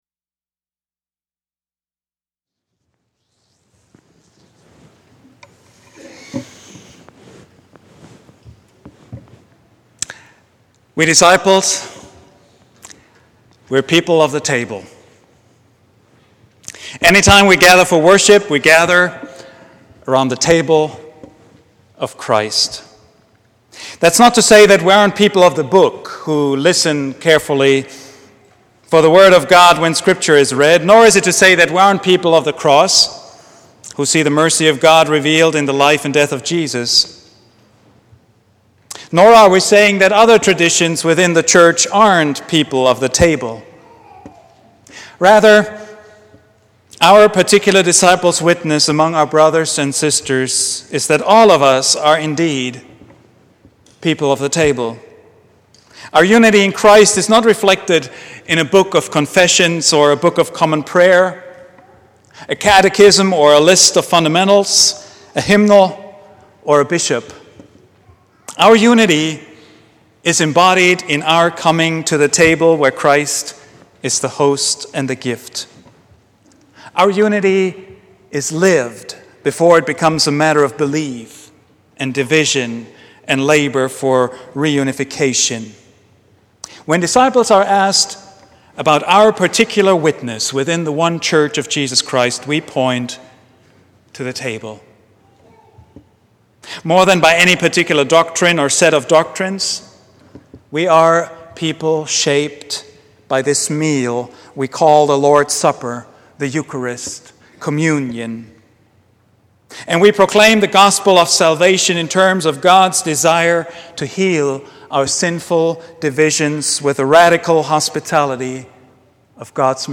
Thank God for the Table — Vine Street Christian Church